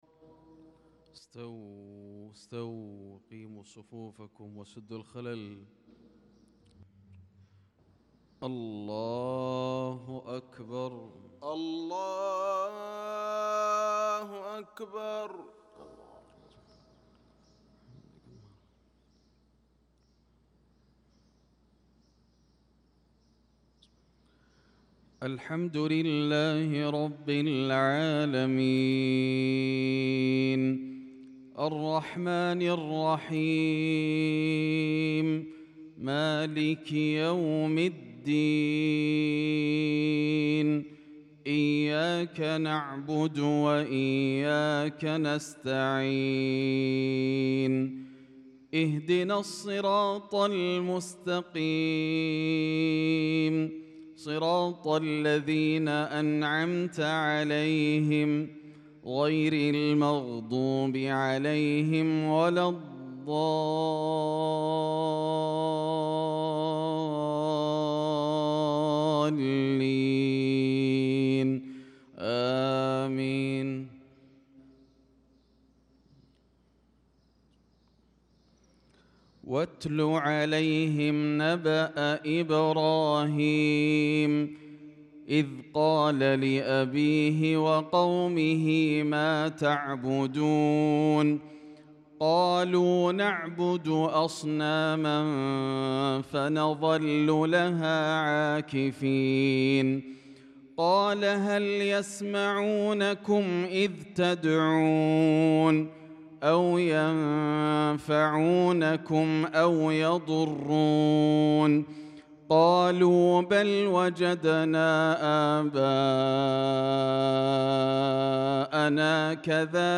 صلاة الفجر للقارئ ياسر الدوسري 22 شوال 1445 هـ
تِلَاوَات الْحَرَمَيْن .